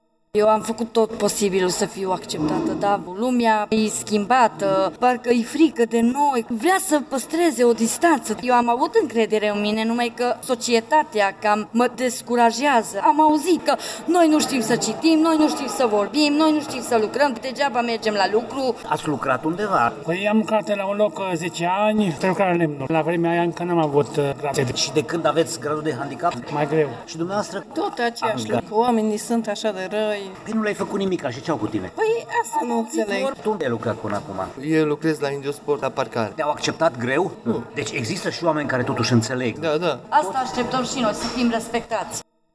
Aceste persoane spun că se simt marginalizate și ar avea nevoie de mai multă deschidere din partea celorlalți: